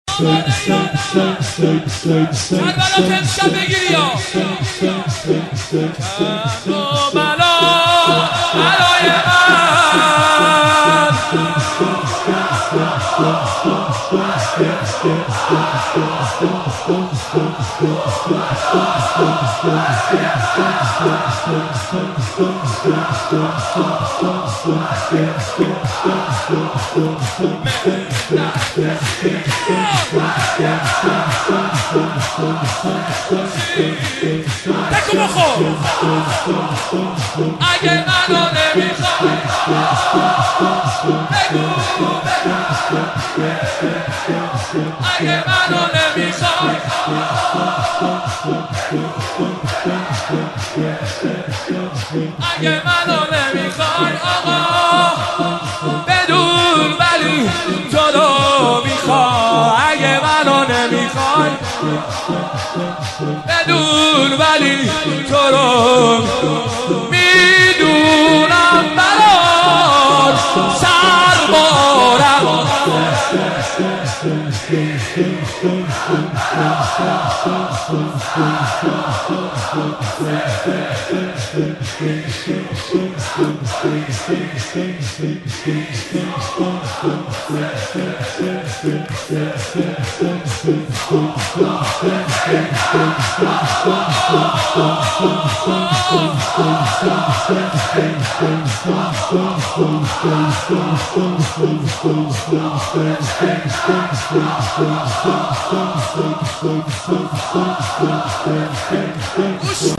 شب اول محرم
شور